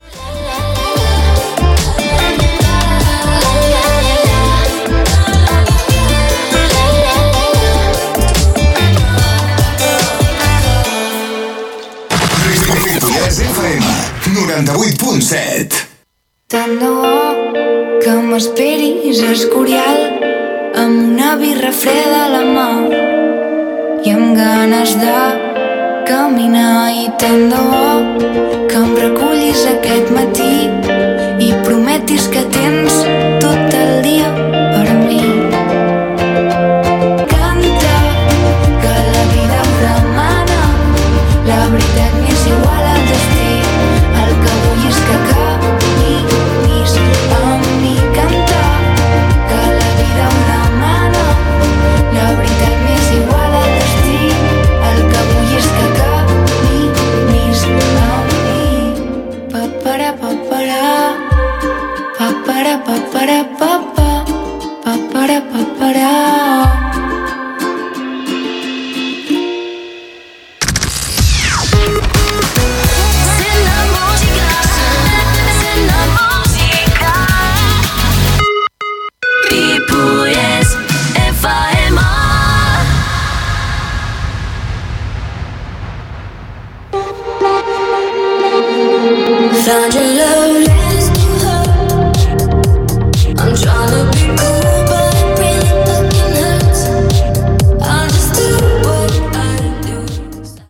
Tema musical, indicatiu
Gènere radiofònic Musical